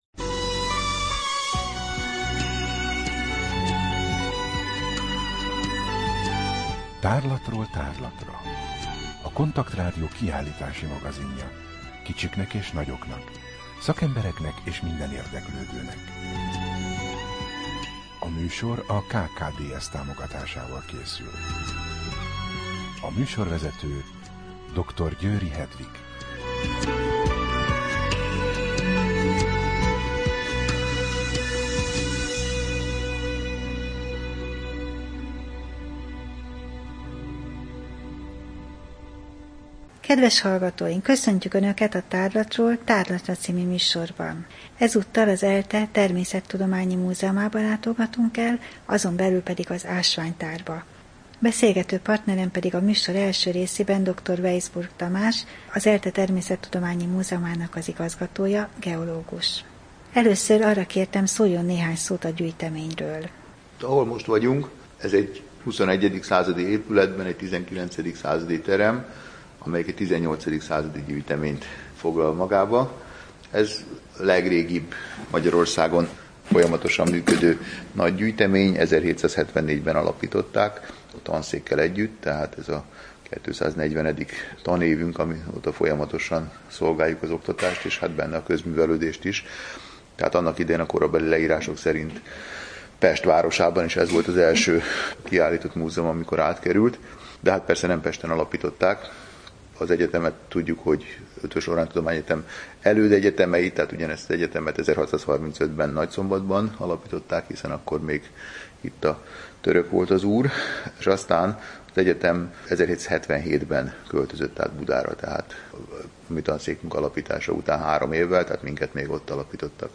Rádió: Tárlatról tárlatra Adás dátuma: 2014, Január 27 Tárlatról tárlatra / KONTAKT Rádió (87,6 MHz) 2014 január 27. A műsor felépítése: I. Kaleidoszkóp / kiállítási hírek II. Bemutatjuk / ELTE Természetrajzi Múzeum Ásvány- és Kőzettára, Budapest A műsor vendége